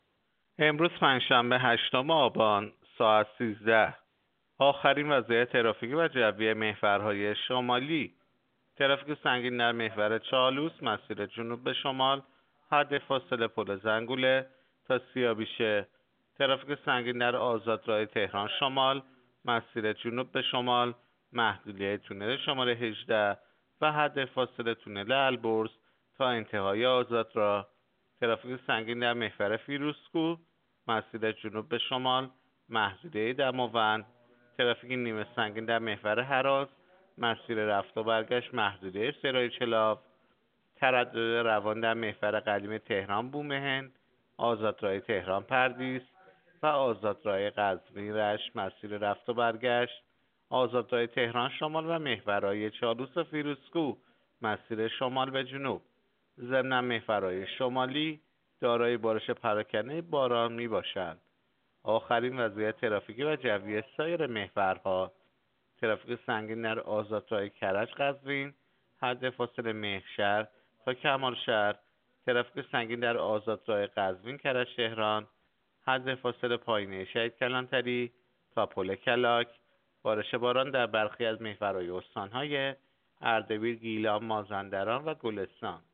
گزارش رادیو اینترنتی از آخرین وضعیت ترافیکی جاده‌ها ساعت ۱۳ هشتم آبان؛